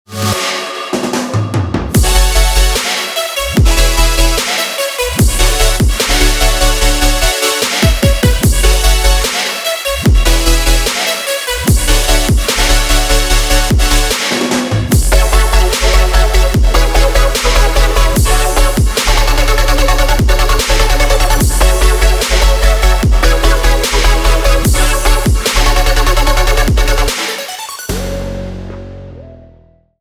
Sunset for Serum (Melodic Serum Presets)